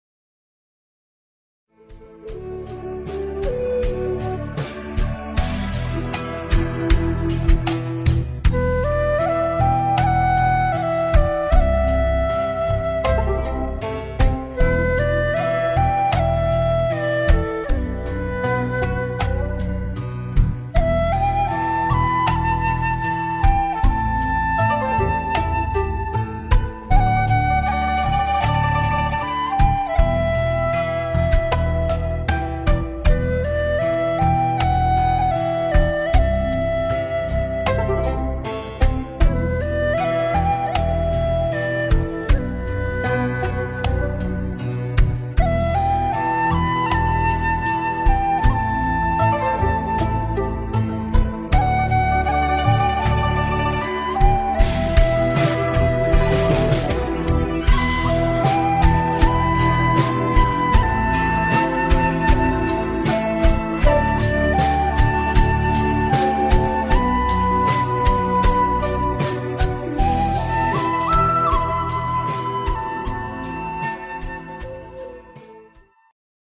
giai điệu cổ phong da diết.
bản không lời chất lượng cao